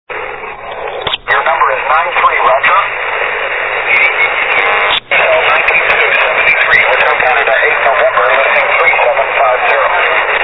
Všechny nahrávky pocházejí z FT817.